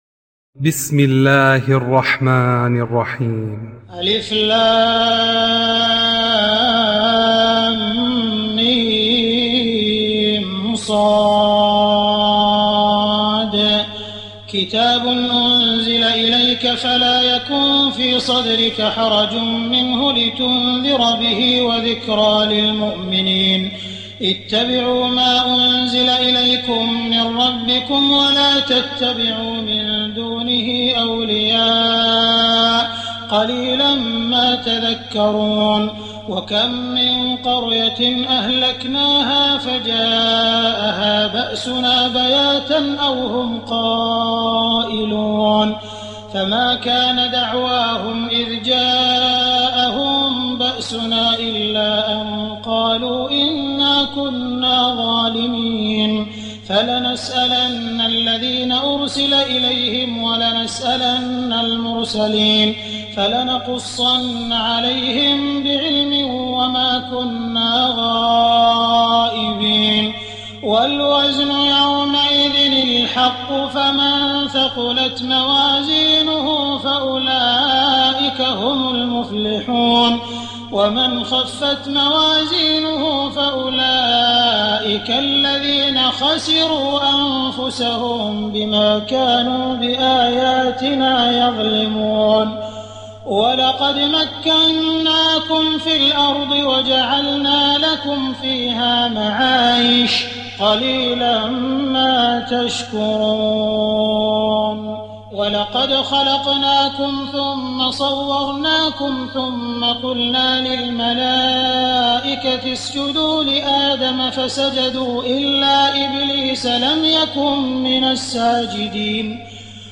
تراويح الليلة السابعة رمضان 1419هـ من سورة الأعراف (1-93) Taraweeh 7 st night Ramadan 1419H from Surah Al-A’raf > تراويح الحرم المكي عام 1419 🕋 > التراويح - تلاوات الحرمين